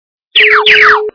- звуки для СМС
При прослушивании Звук - SMS от инопланетянина качество понижено и присутствуют гудки.
Звук Звук - SMS от инопланетянина